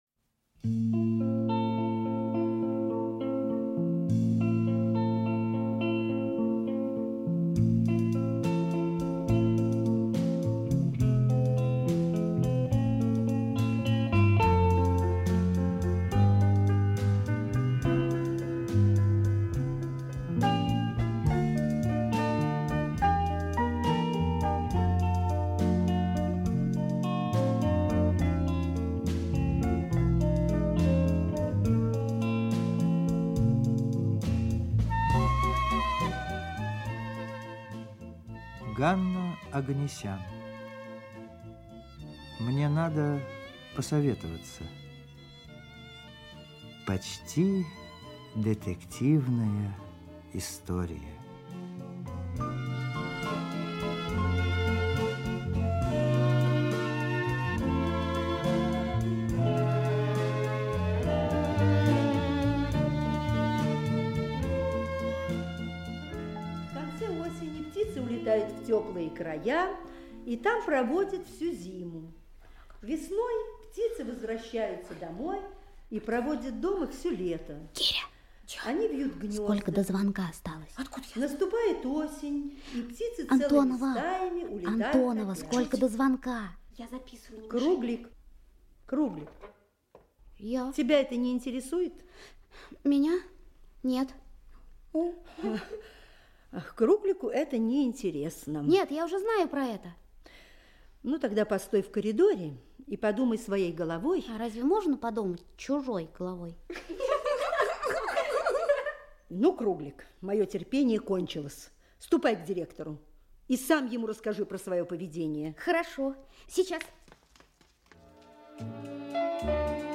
Аудиокнига Мне надо посоветоваться | Библиотека аудиокниг
Aудиокнига Мне надо посоветоваться Автор Ганна Оганесян Читает аудиокнигу Актерский коллектив.